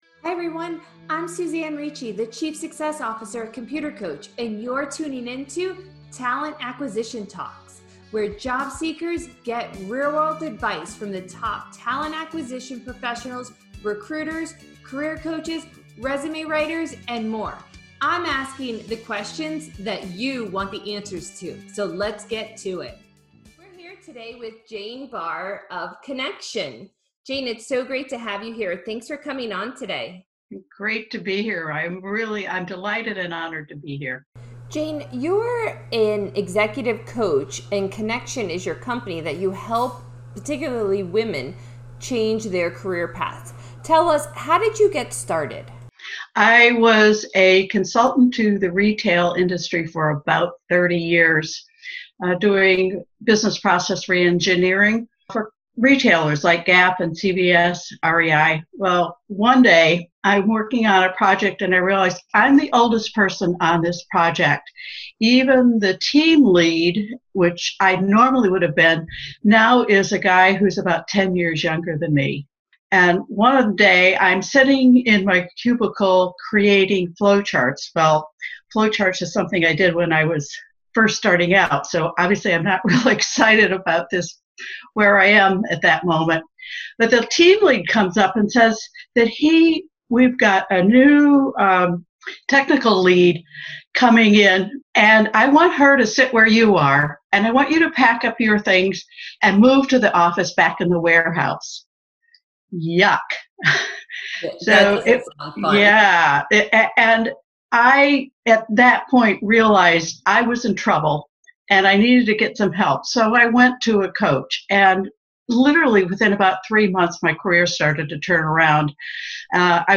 We hope you enjoyed the conversation and gained valuable insights for your tech journey and IT career.